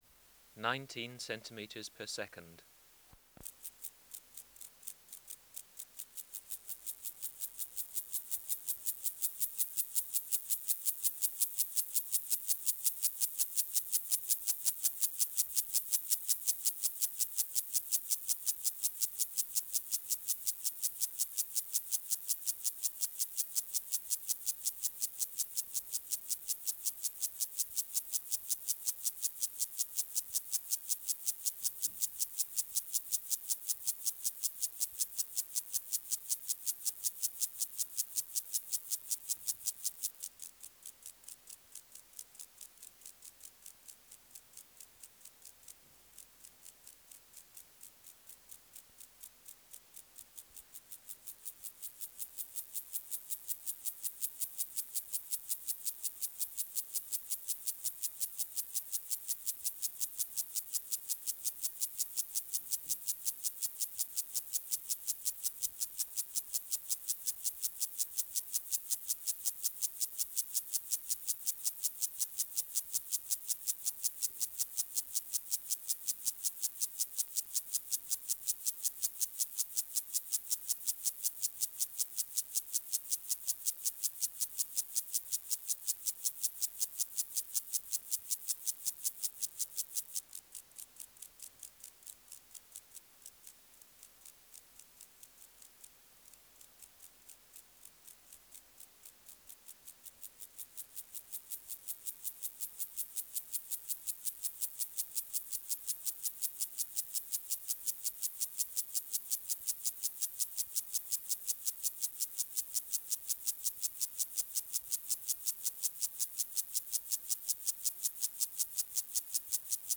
Species: Gomphocerus sibiricus
Recording Location: BMNH Acoustic Laboratory
Reference Signal: 1 kHz for 10 s
Substrate/Cage: Small recording cage Biotic Factors / Experimental Conditions: Courting a dying female 1 cm away
Microphone & Power Supply: Sennheiser MKH 405 Distance from Subject (cm): 5